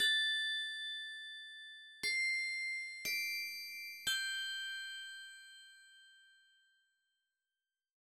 28 Bells PT2.wav